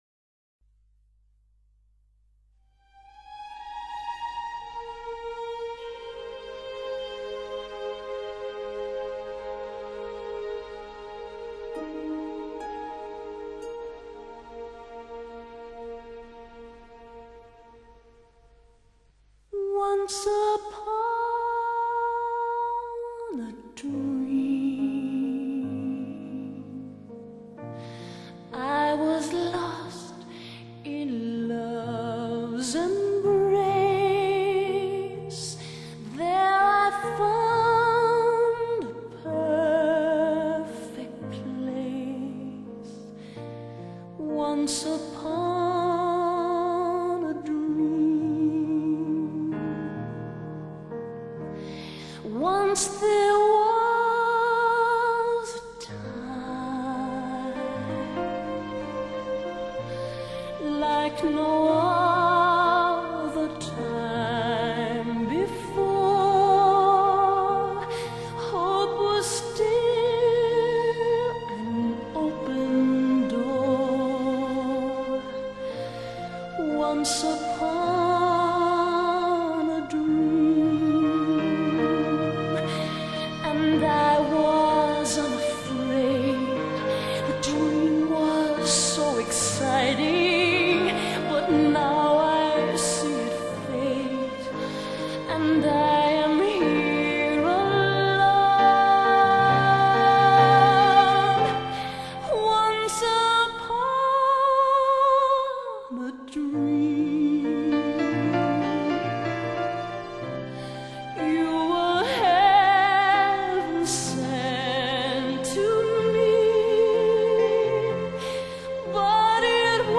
这18段绝美的女声录音，是我们从成百上千张天碟级唱片中精挑细筛遴选而出的。